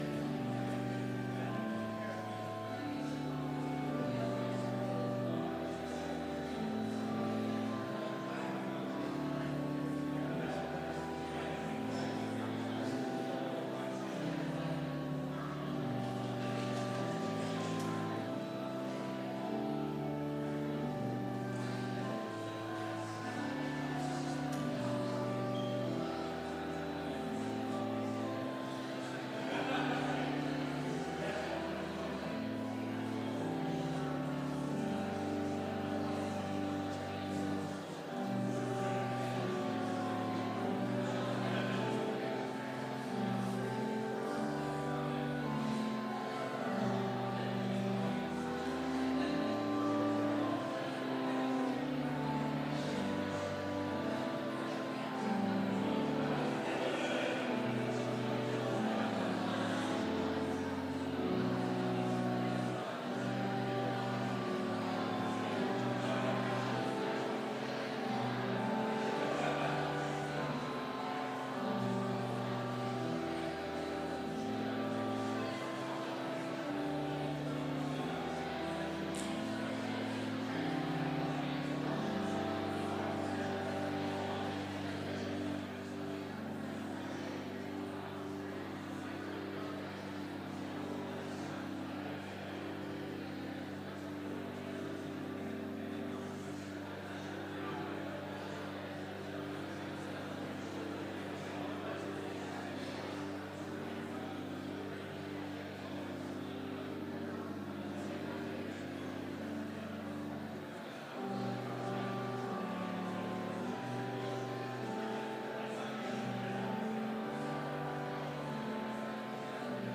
Complete service audio for Chapel - August 22, 2019